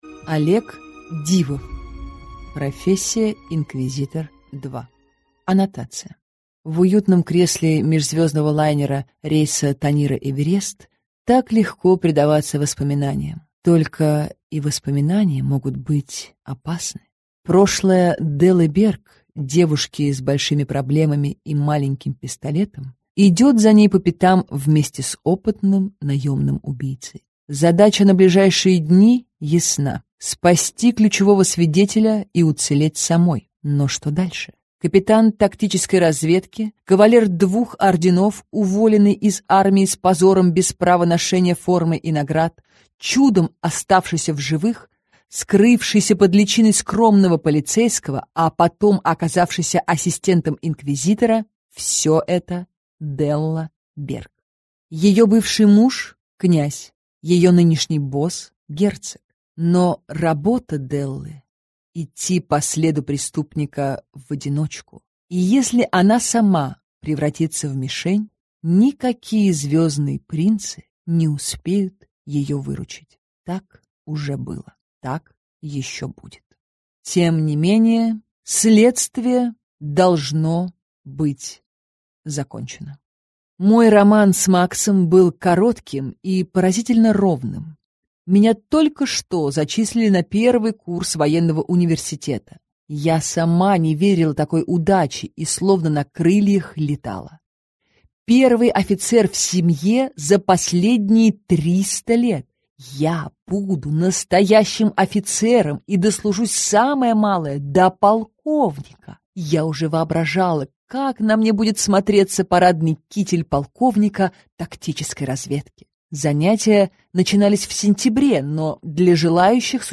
Аудиокнига Леди не движется – 2 | Библиотека аудиокниг